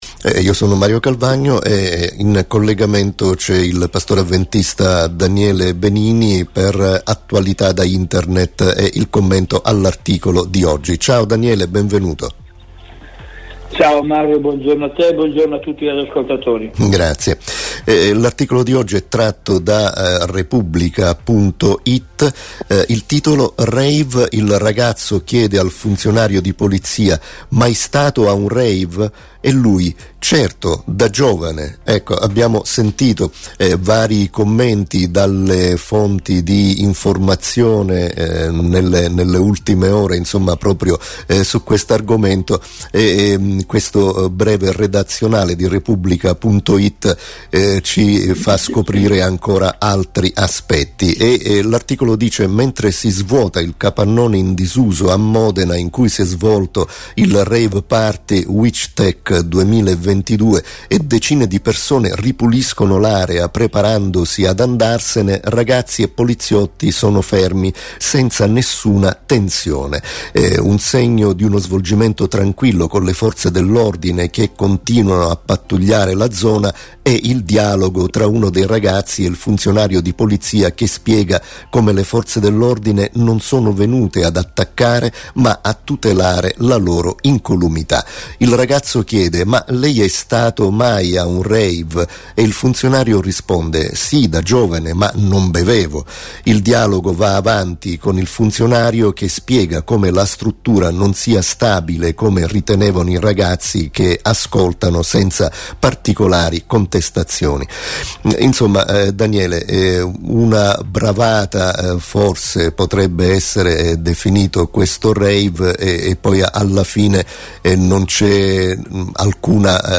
pastore avventista.